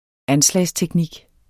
Udtale [ ˈanslæjs- ]